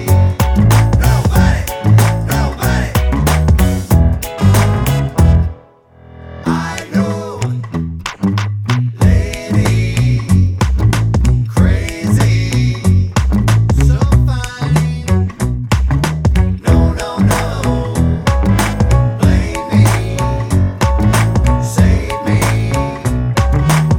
With Rapper Jazz / Swing 3:00 Buy £1.50